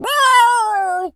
cat_scream_12.wav